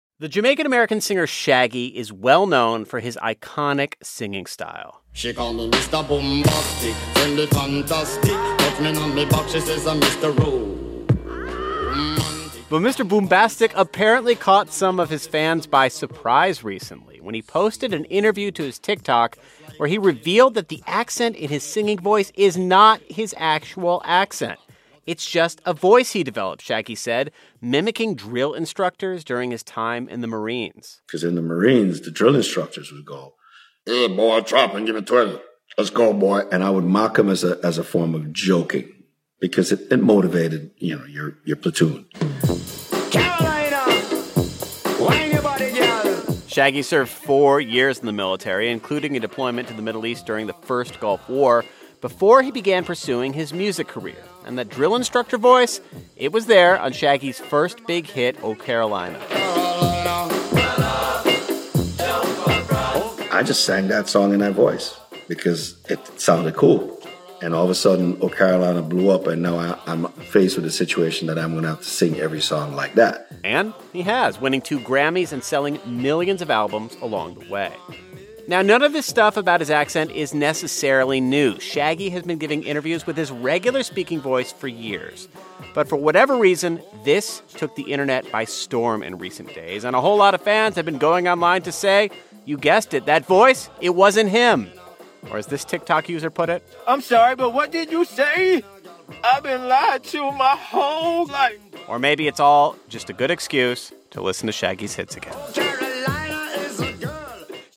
The Jamaican musician Shaggy is known for singing in a Jamaican accent he doesn't use when speaking. Now he's explained the accent's origins.